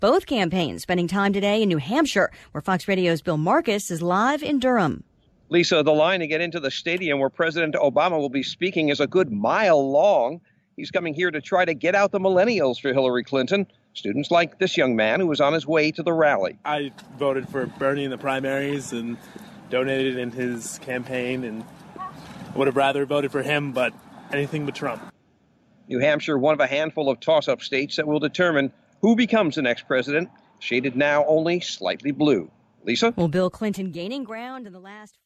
Inside the Whittemore Center Arena at University of New Hampshire in Durham, NH
1PM LIVE –